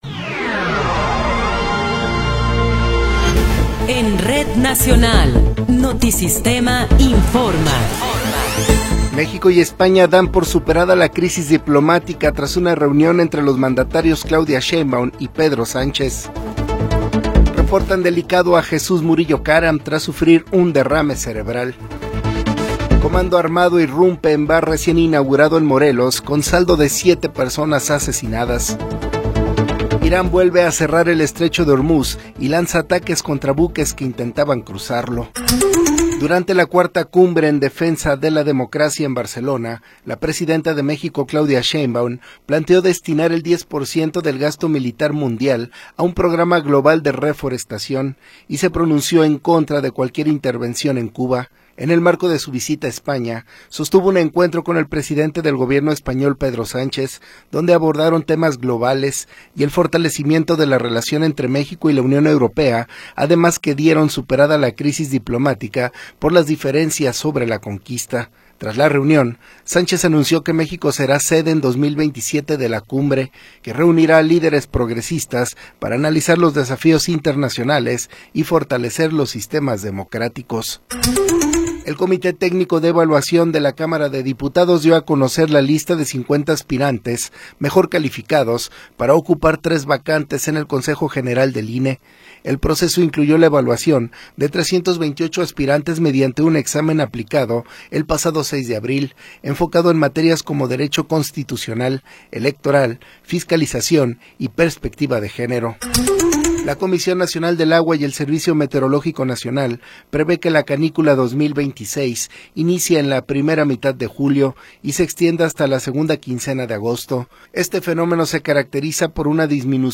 Noticiero 14 hrs. – 18 de Abril de 2026
Resumen informativo Notisistema, la mejor y más completa información cada hora en la hora.